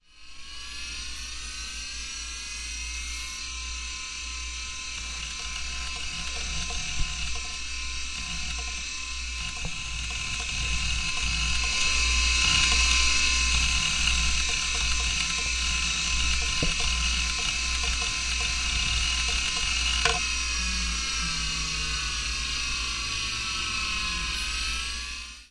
1990年代康柏硬盘 " 电子, 计算机, 硬盘 1990年代康柏硬盘, 转轴, 关机
描述：录制1995 Compaq计算机桌面硬盘。具有电子呼呼，上/下声音，呼呼，点击，“记忆访问”噪音。可以用于例如一个电影场景，背景是计算机，技术故障等等。
Tag: 嗡嗡 咔嗒 电子 磁盘 计算机 伺服 访问 驱动器 硬盘 哼哼 电源 发电机